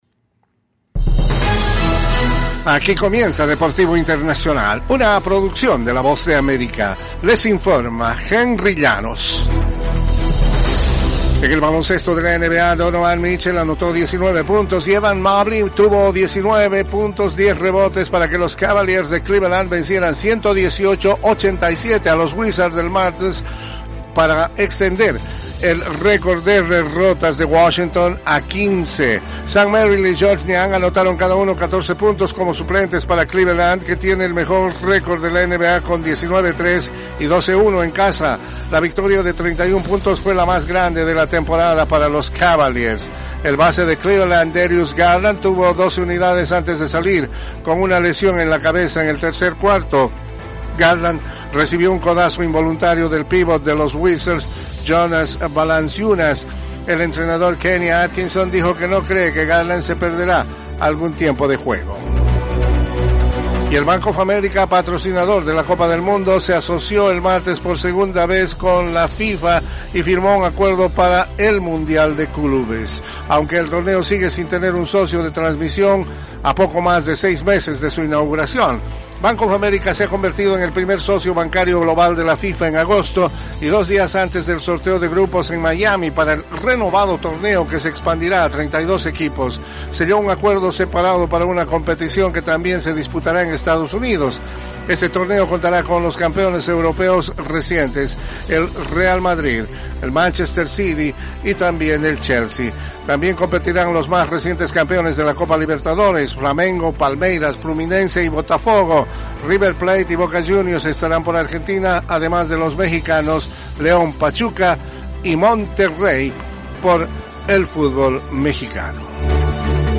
Las noticias deportivas llegan desde los estudios de la Voz de América